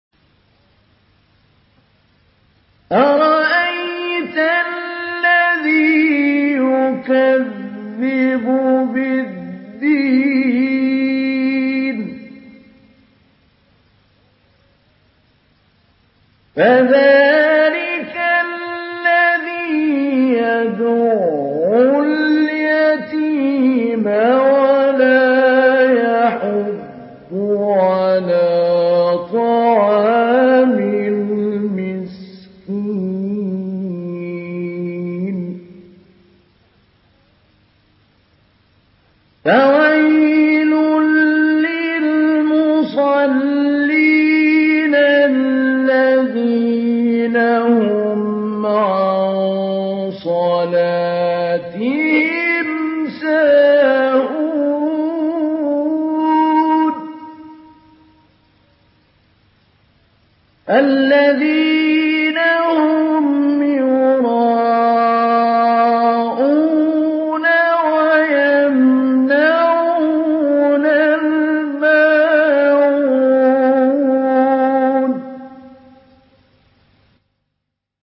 Surah الماعون MP3 in the Voice of محمود علي البنا مجود in حفص Narration
Surah الماعون MP3 by محمود علي البنا مجود in حفص عن عاصم narration.